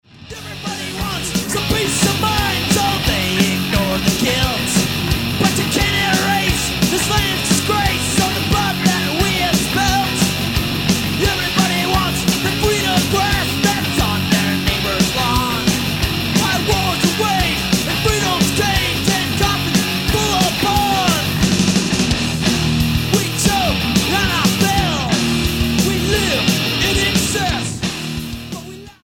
The US-based punk rockers Punk lives
Style: Hard Music